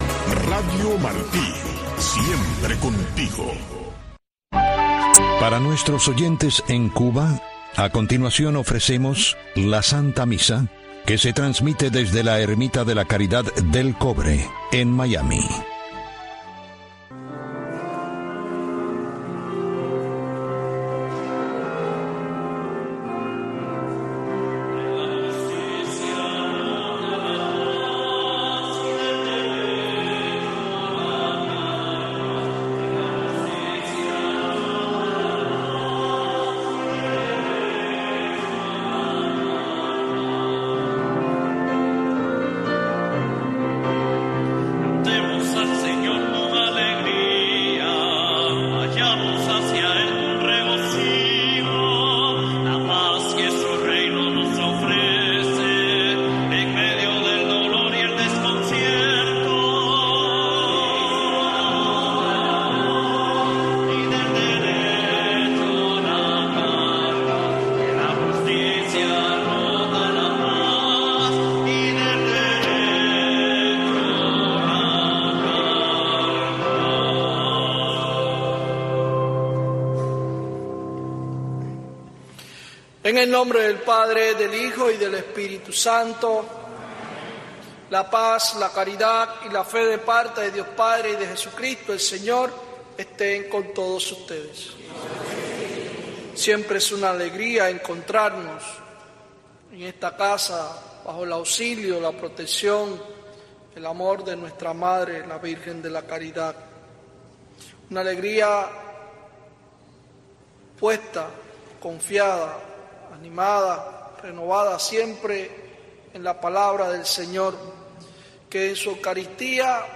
La misa dominical transmitida para Cuba desde el Santuario Nacional de Nuestra Señora de la Caridad, un templo católico de la Arquidiócesis de Miami dedicado a la Patrona de Cuba.